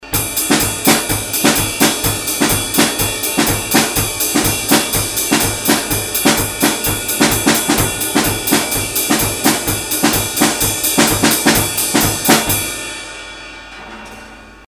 bubblin.mp3